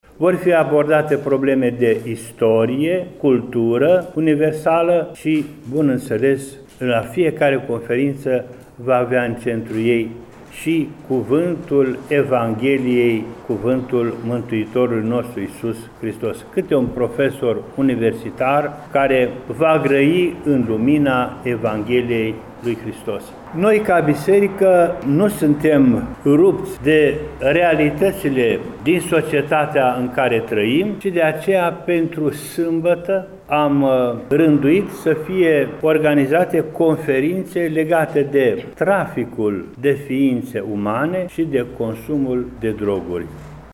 Înalpreasfinția Sa Ioan, Mitropolitul Banatului,  spune că ediția de la Timișoara are ca tematică prietenia, iar cu tinerii se va discuta inclusiv despre droguri și trafic de persoane.